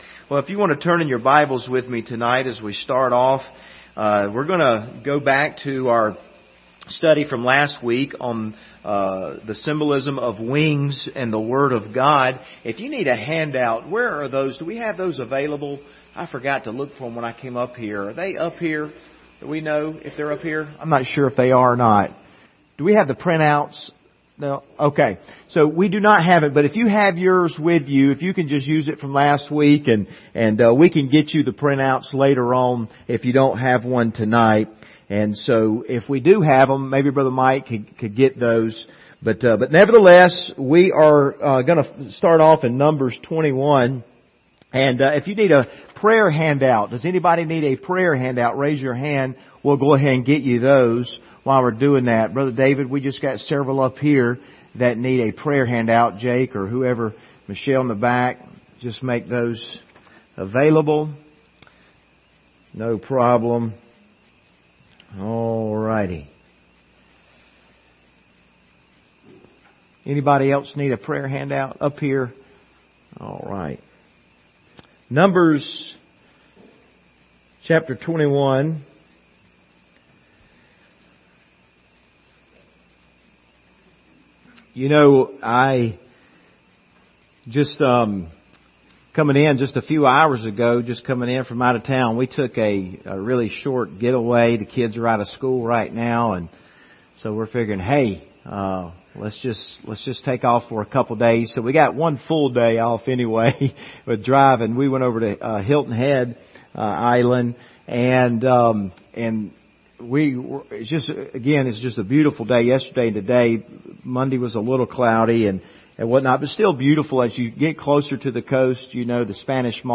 Service Type: Wednesday Evening Topics: holiness of God